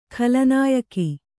♪ khala nāyaki